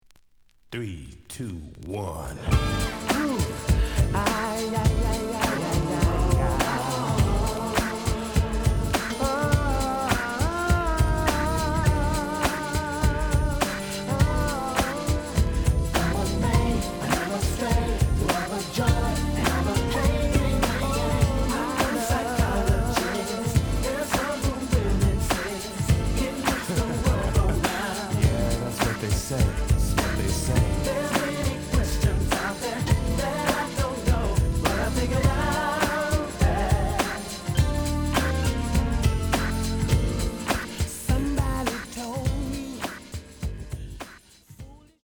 試聴は実際のレコードから録音しています。
●Genre: Hip Hop / R&B